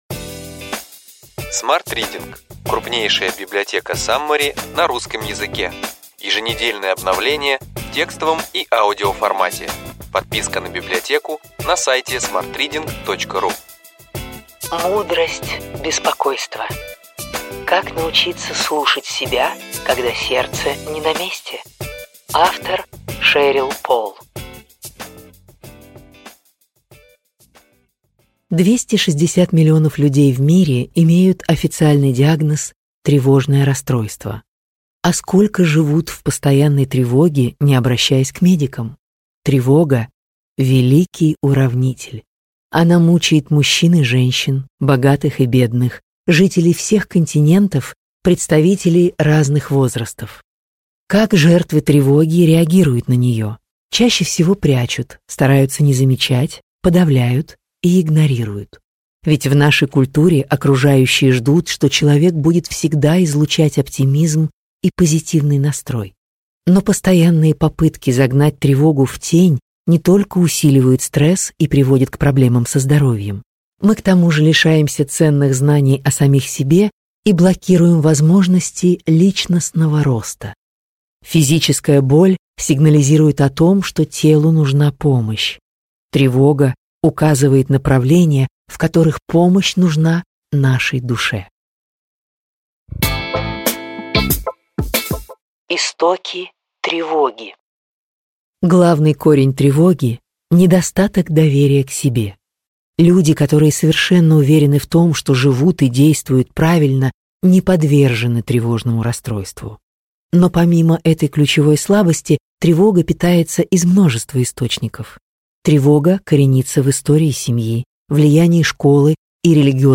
Аудиокнига Ключевые идеи книги: Мудрость беспокойства. Как научиться слушать себя, когда сердце не на месте | Библиотека аудиокниг